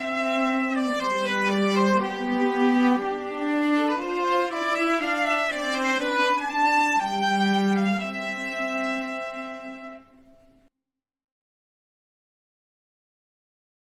今回は弦楽器の二重奏的な演奏を作成するため、ビオラとチェロのAI楽器をそれぞれ選び、トラックを作成しました。
打ち込んだMIDIデータは各ノートのピッチとデュレーションのみを指定しただけですが、どちらのパートにも自然なアーティキュレーション表現が加味されていることがわかると思います。